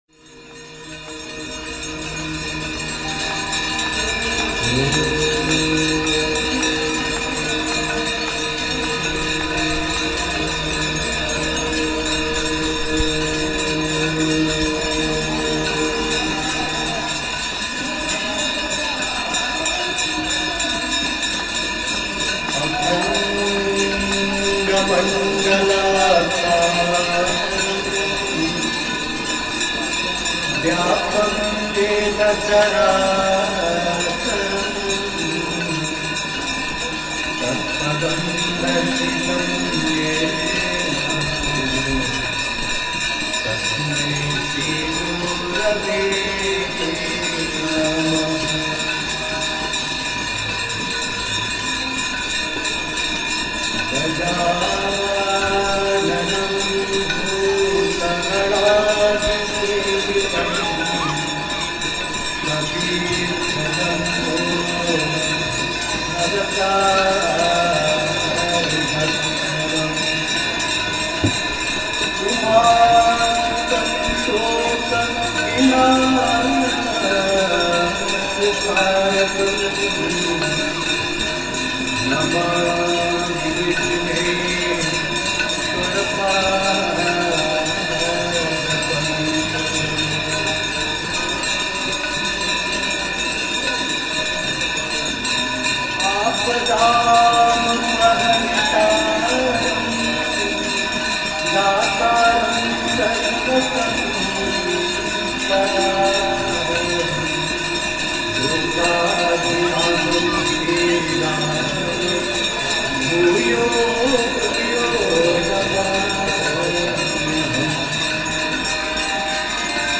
Dashashwamedh Ghat is the main ghat in Varanasi on the Ganga River. Ganga Aarti (ritual of offering prayer to the Ganges river) is held daily at dusk.
Several priests perform this ritual by carrying deepam and moving it up and down in a rhythmic tune of bhajans.